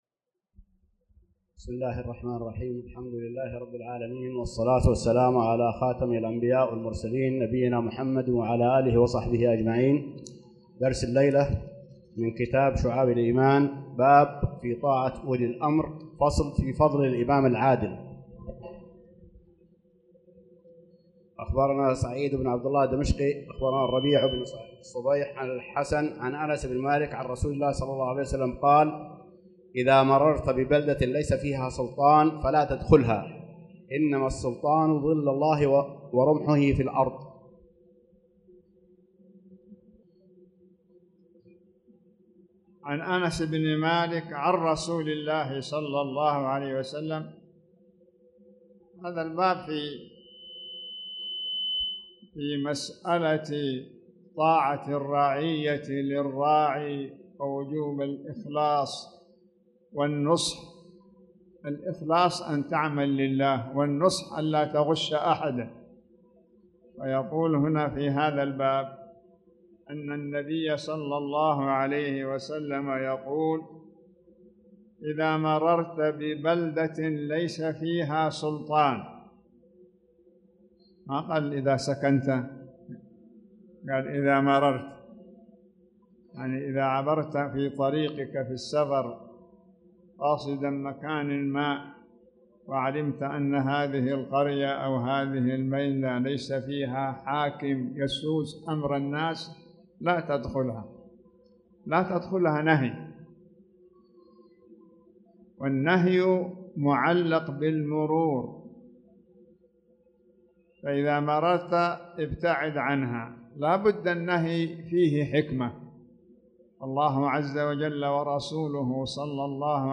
تاريخ النشر ١٢ شوال ١٤٣٧ هـ المكان: المسجد الحرام الشيخ